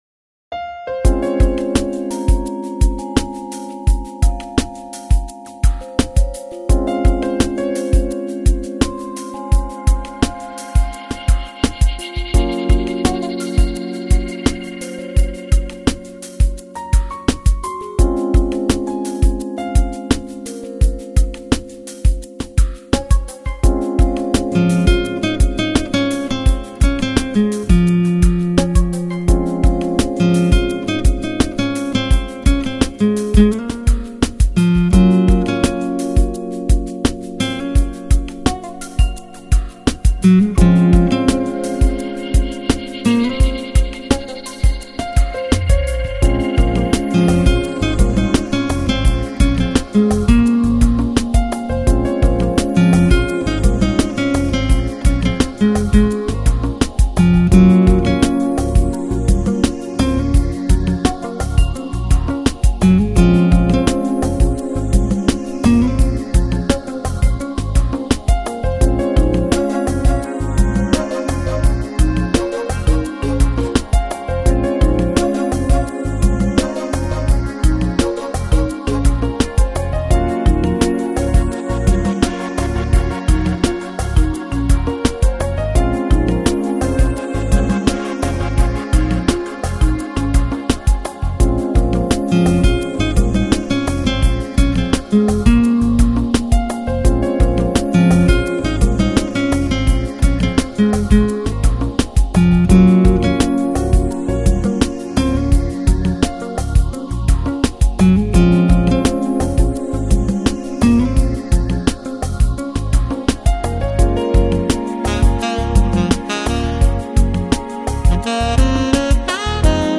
Chill Out, Lounge, Smooth Jazz Год издания